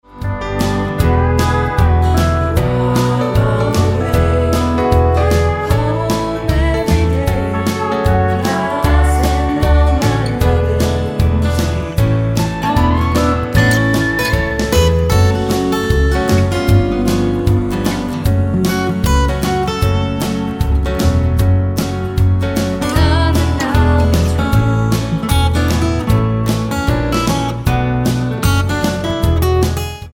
--> MP3 Demo abspielen...
Tonart:A mit Chor